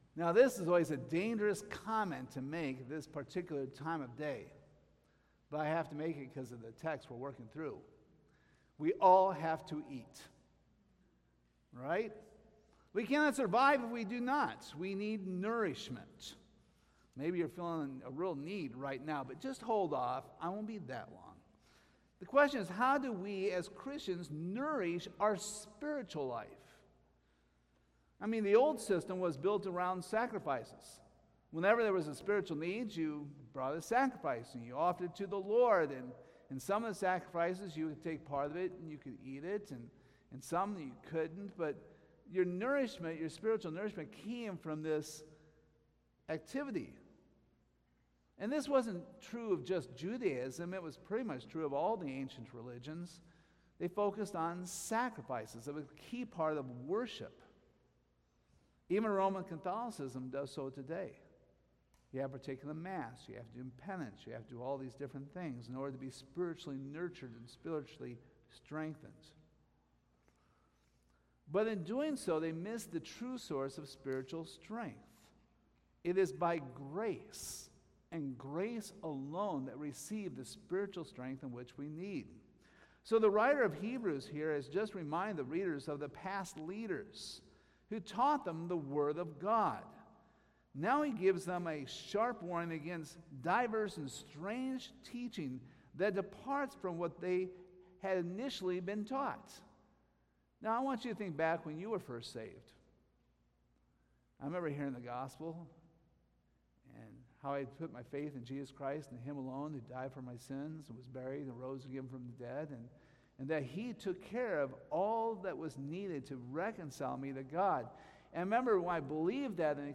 Hebrews 13:9-16 Service Type: Sunday Morning We hope you were blessed and challenged by the ministry of Calvary Baptist Church.